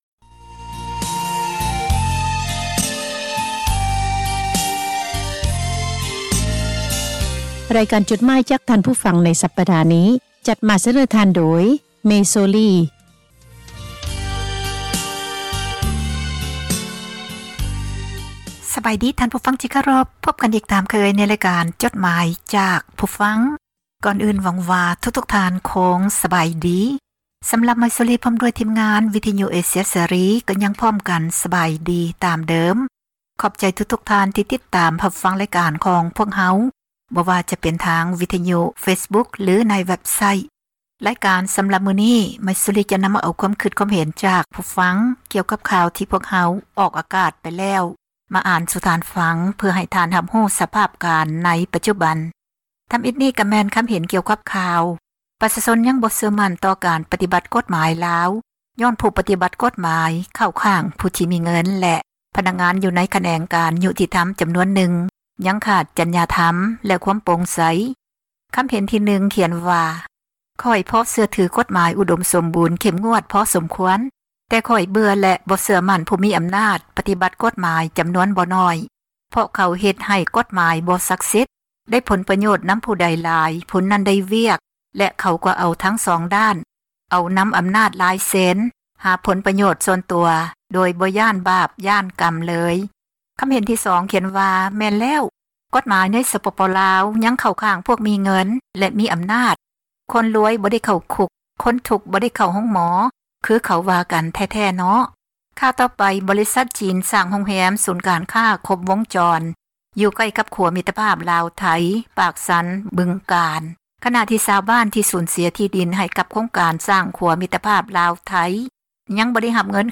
( ເຊີນທ່ານ ຟັງຣາຍລະອຽດ ຈາກສຽງບັນທຶກໄວ້) ໝາຍເຫດ: ຄວາມຄິດຄວາມເຫັນ ຂອງຜູ່ອ່ານ ທີ່ສະແດງອອກ ໃນເວັບໄຊທ໌ ແລະ ເຟສບຸກຄ໌ ຂອງວິທຍຸ ເອເຊັຽ ເສຣີ, ພວກເຮົາ ທິມງານ ວິທຍຸເອເຊັຽເສຣີ ໃຫ້ຄວາມສຳຄັນ ແລະ ຂອບໃຈ ນຳທຸກໆຖ້ອຍຄຳ, ແລະ ມີໜ້າທີ່ ນຳມາອ່ານໃຫ້ທ່ານ ໄດ້ຮັບຟັງກັນ ແລະ ບໍ່ໄດ້ເສກສັນປັ້ນແຕ່ງໃດໆ, ມີພຽງແຕ່ ປ່ຽນຄຳສັພ ທີ່ບໍ່ສຸພາບ ໃຫ້ເບົາລົງ ເທົ່ານັ້ນ. ດັ່ງນັ້ນ ຂໍໃຫ້ທ່ານຜູ່ຟັງ ຈົ່ງຕັດສິນໃຈເອົາເອງ ວ່າ ຄວາມຄິດເຫັນນັ້ນ ເປັນໜ້າເຊື່ອຖື ແລະ ຄວາມຈິງ ຫລາຍ-ໜ້ອຍ ປານໃດ.